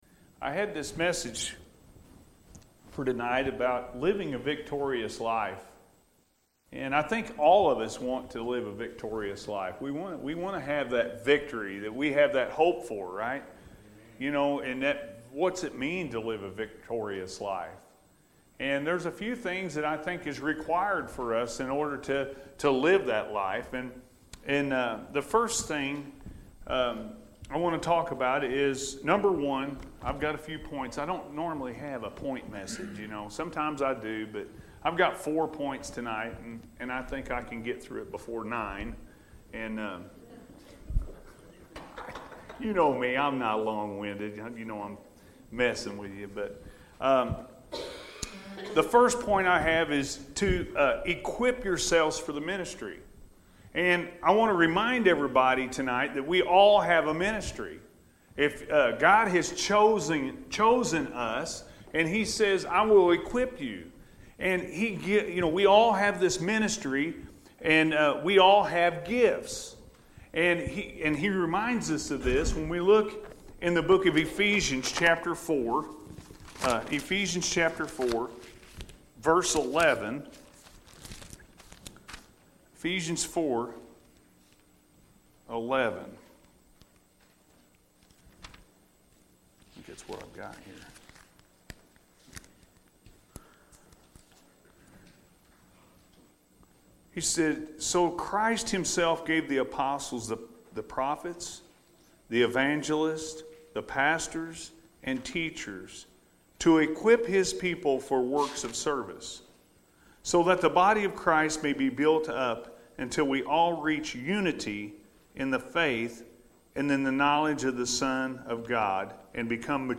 Living A Victorious Life-P.M. Service – Anna First Church of the Nazarene